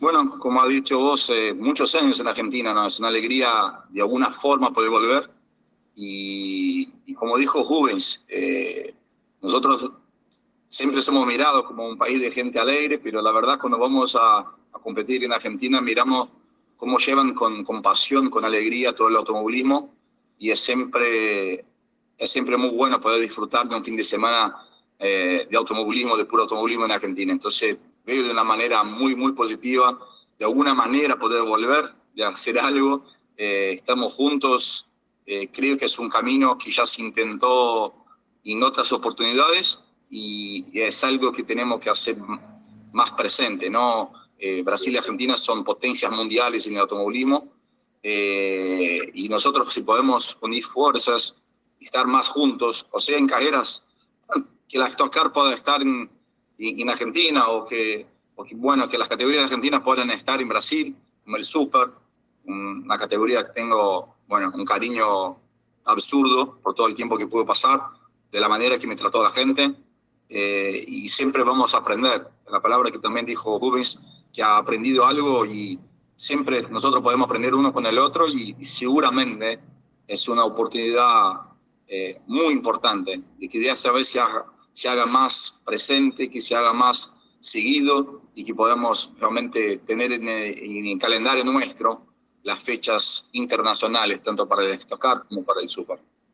El experimentado piloto brasilero estuvo presente de manera virtual en la conferencia de prensa en el ACA y manifestó su alegría de la nueva alianza generada entre la agencia Tango y VICAR, para competir el TC2000 y el Stock Car en conjunto.